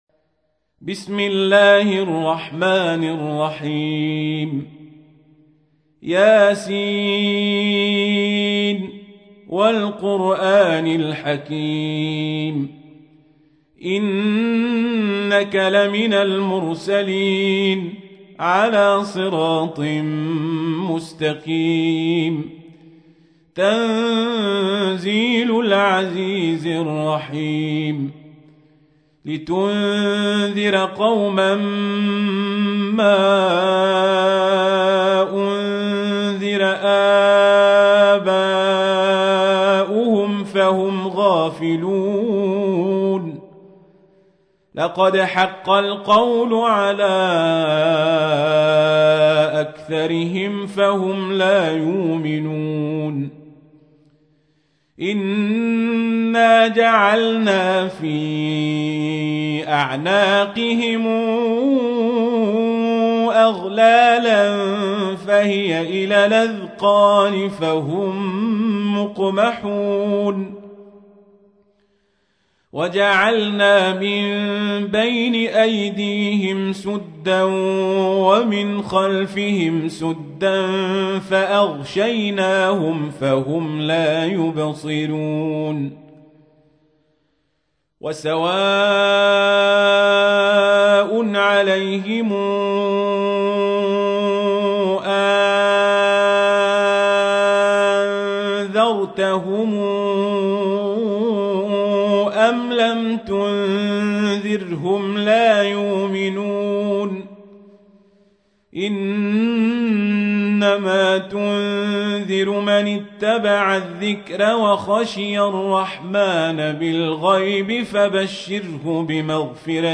تحميل : 36. سورة يس / القارئ القزابري / القرآن الكريم / موقع يا حسين